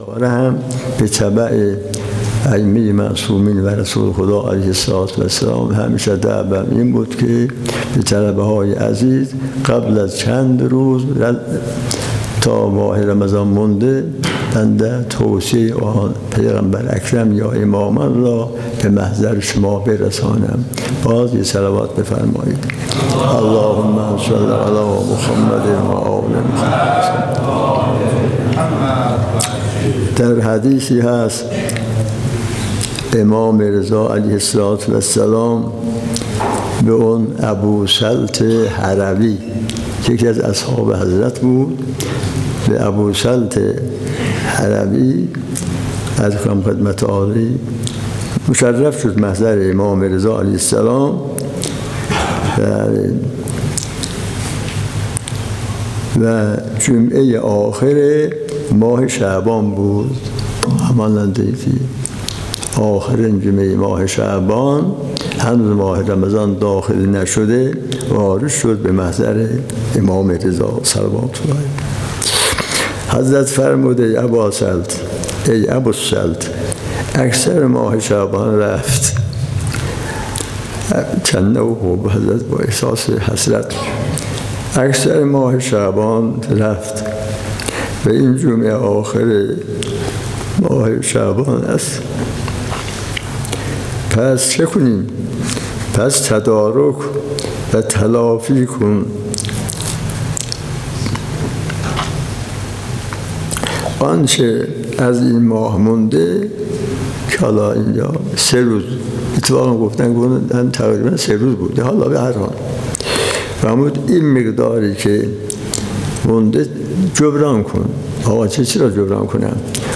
سخنرانی مذهبی